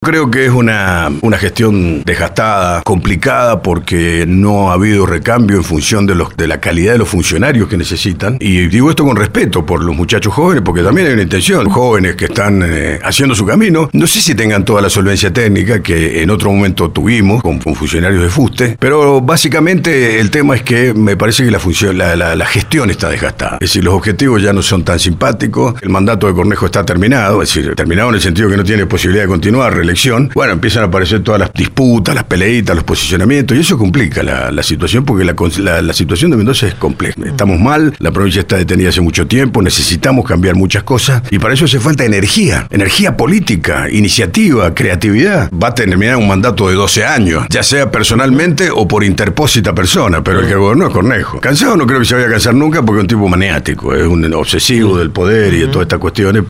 Que “Cornejo está desgastado”, no fue la única frase explosiva que largó el senador Martín Rostand (Unión Mendocina) en LV18.
Durante la entrevista, hizo un repaso de su visión del rol legislativo y sus coincidencias con las ideas de Javier Milei.